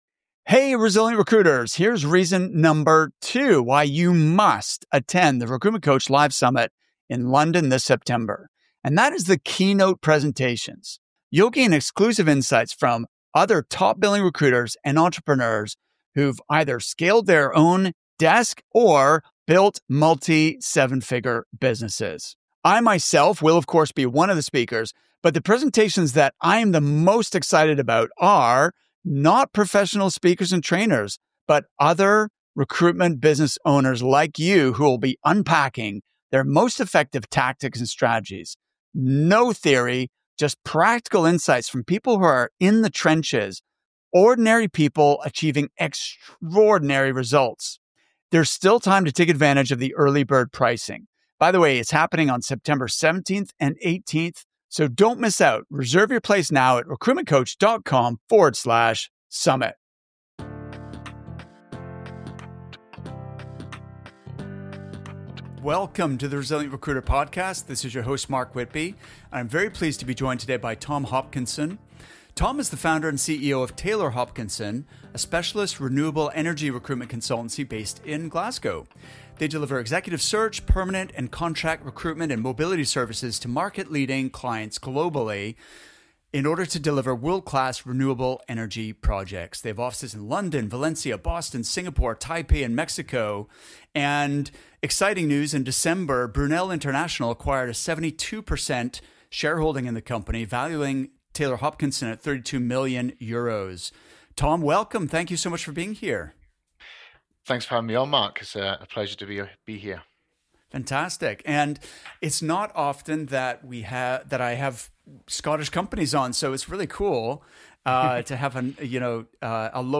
Many founders dream of selling their business one day. In this interview, you’ll hear from someone who’s actually done it.